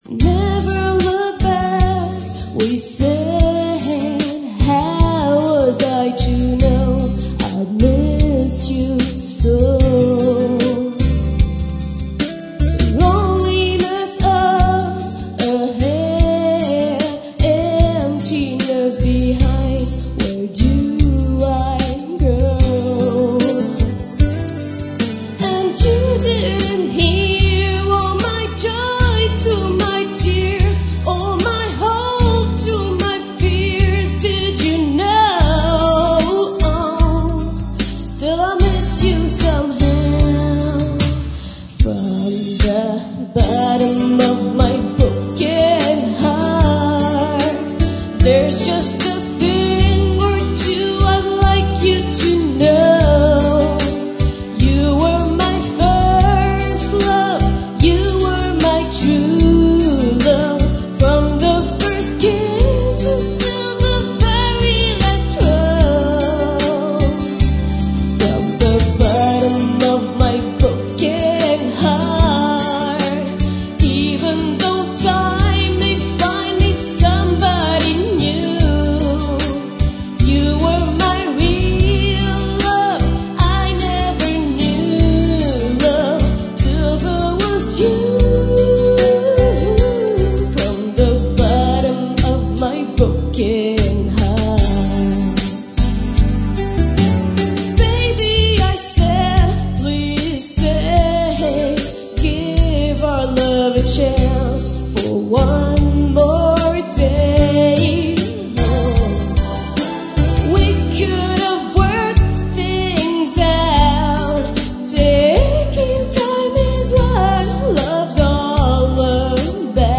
English Karaoke Talented Singers